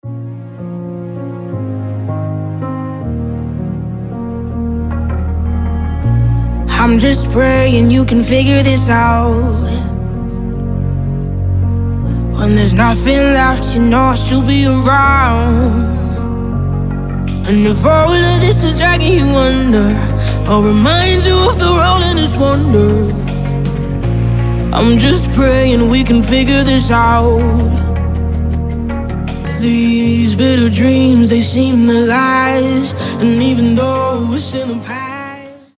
• Качество: 320, Stereo
женский голос
спокойные
нарастающие
пианино